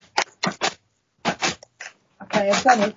Removing or reduceing the sound of a perkins Brailler
It sounds very like an old-fashioned mechanical typewriter …